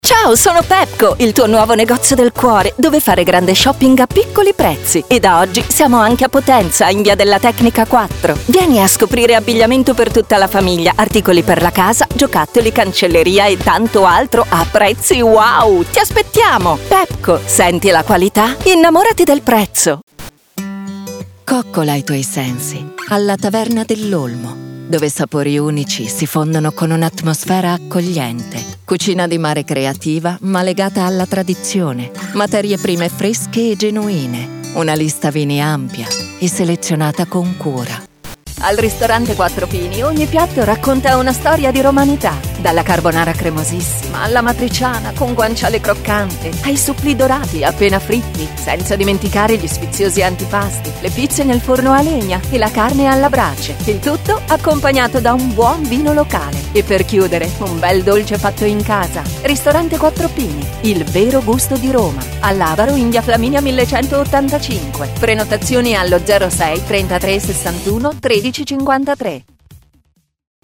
articulate, authentic, Deep, elegant, empathic, Formal, friendly
I record every day in my Home Studio: commercials, e-learning courses, tutorials, documentaries, audio guides, messages for telephone exchanges, audio for promo videos, narrations for radio broadcasts, audiobooks, audio for videogames, and much more.